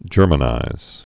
(jûrmə-nīz)